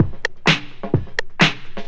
Electrohouse Loop 128 BPM (8).wav